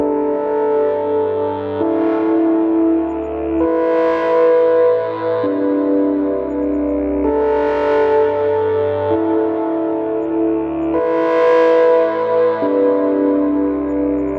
描述：天际合成器声音我使用一个巨大的vst合成器
Tag: 模拟 VSTS 合成器